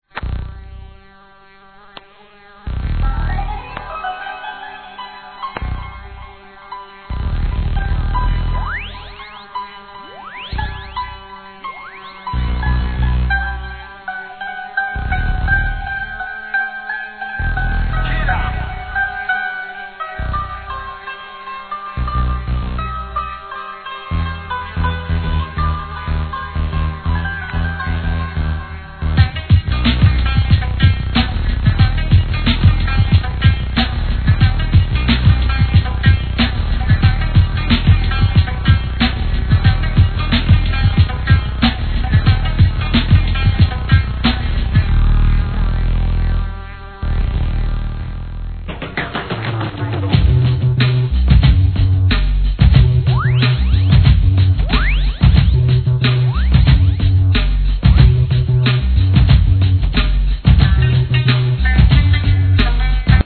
B級映画の様な怪しさ満点のブレイクビーツ!!